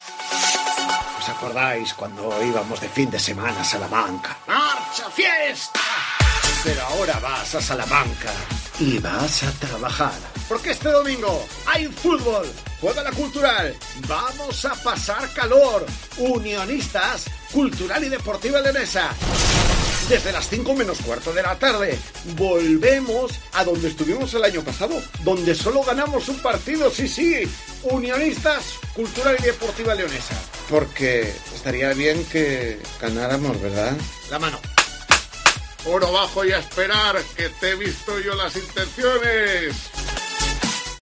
Escucha la cuña promocional del partido Unionistas-Cultural el día 05-09-21 a las 17:00 h en el 1.215 OM